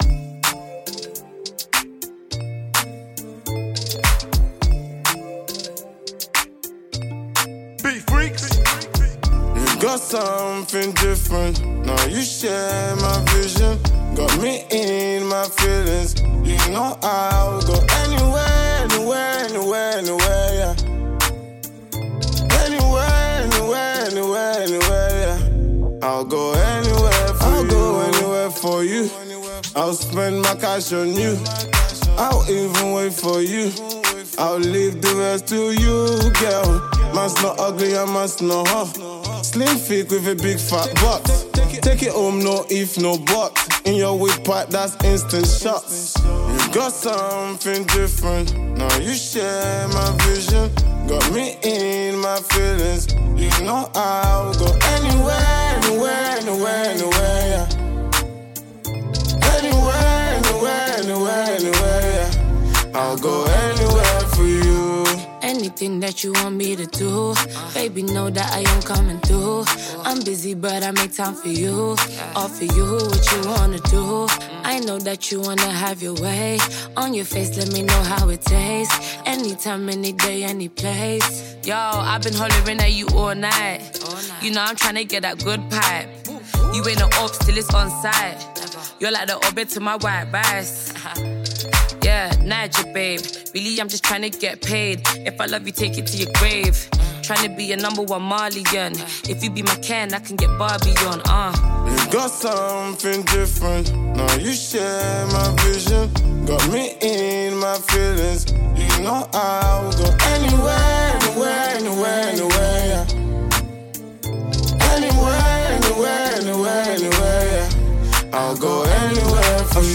South London female rapper